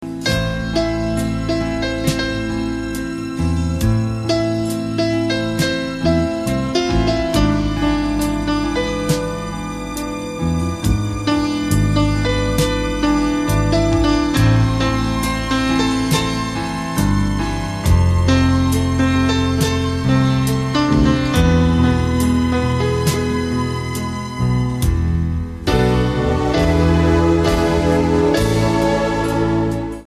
рояль с оркестром). Просто и красиво.